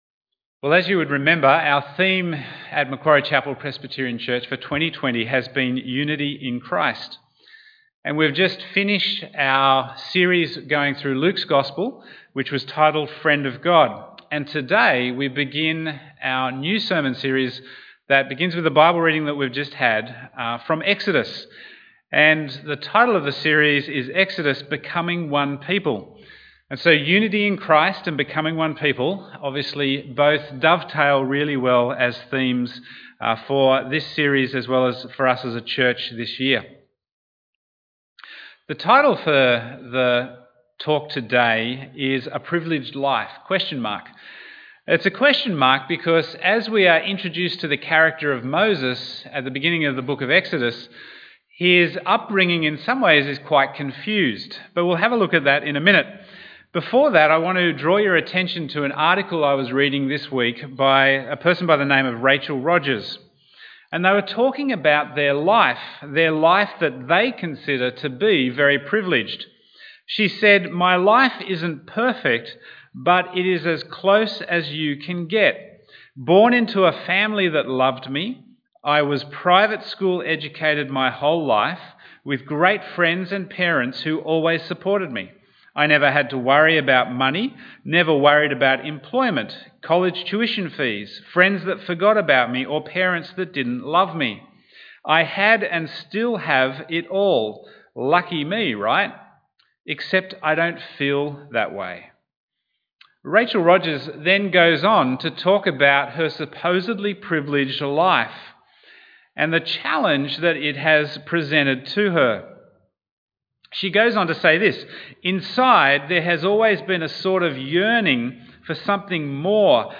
Bible Text: Exodus 2:1-10 | Preacher